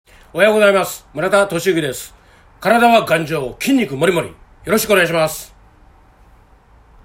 出⾝地・⽅⾔ 兵庫県・関西弁
ボイスサンプル